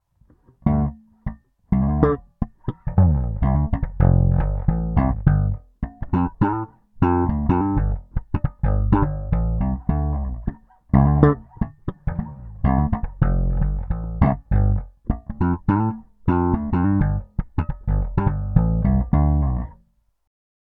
If your looking for a large full warm tone from your bass these are the pickups.
Super Neo – Slap – Tone Down
Super-Neo-Slap-Tone-DOWN.m4a